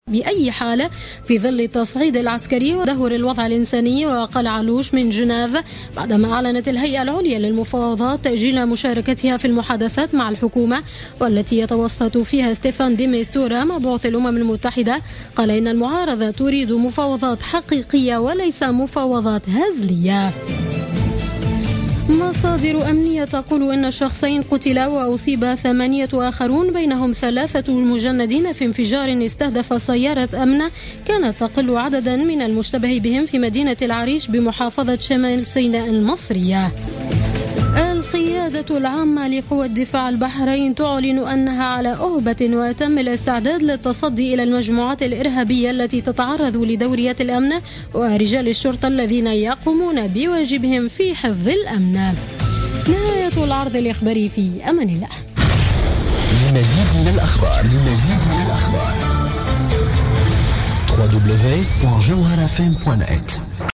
نشرة أخبار منتصف الليل ليوم الثلاثاء 19 أبريل 2016